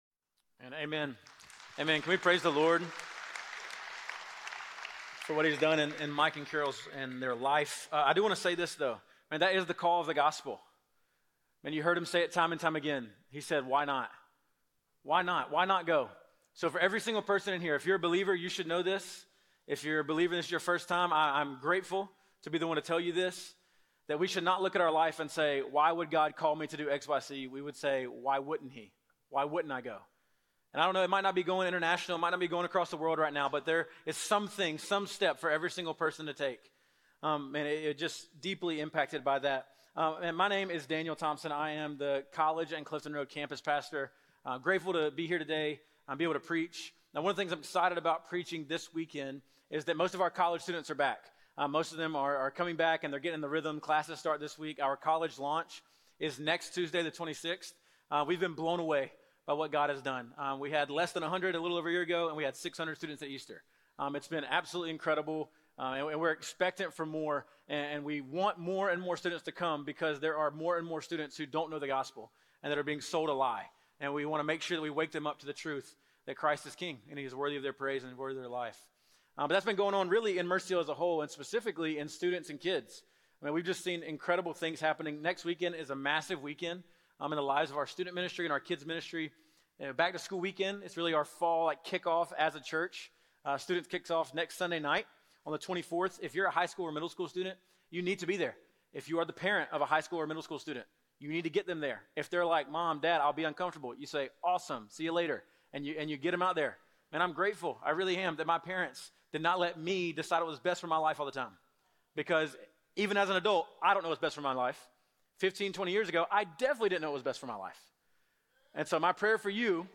Believers Rejoice - Revelation 19:1-10 Sermon